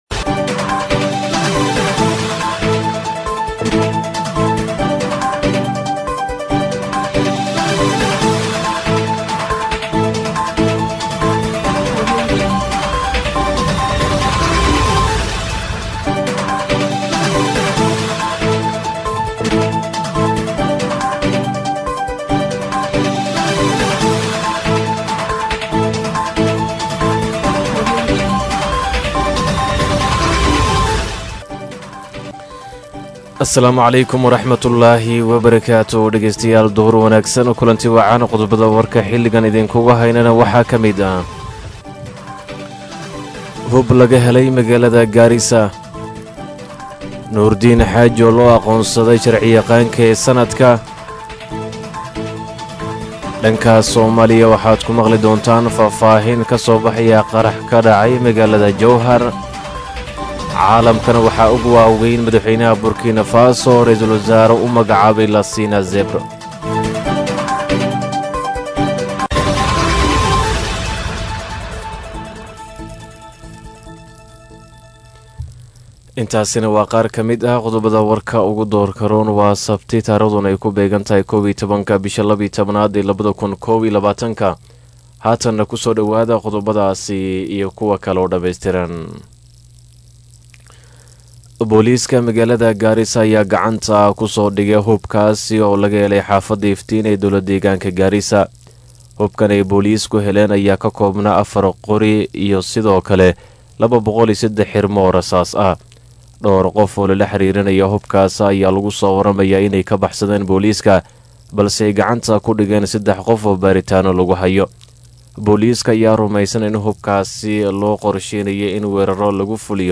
DHAGEYSO:DHAGEYSO:WARKA DUHURNIMO EE IDAACADDA STAR FM